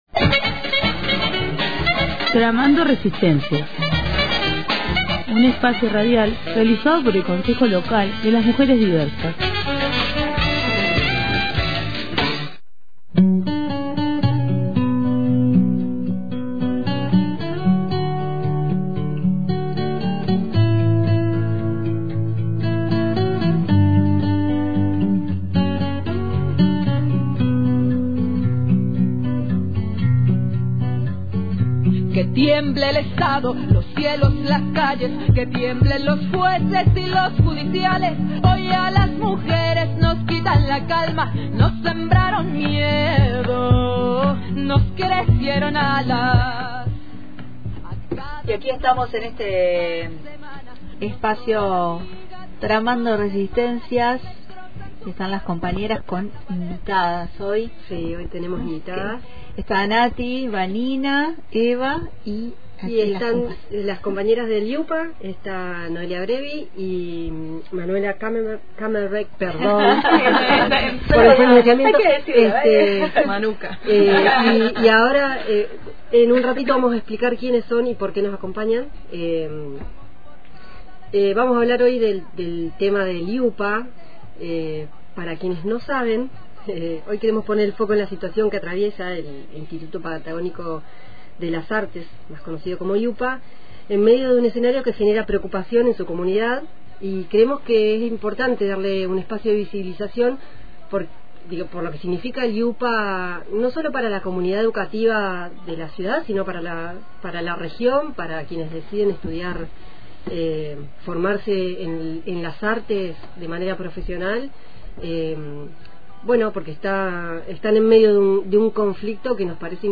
Las entrevistadas remarcaron que la disputa no es solo por una conducción, sino por el sentido mismo de la universidad pública, su institucionalidad y el derecho de la comunidad educativa a participar de manera democrática.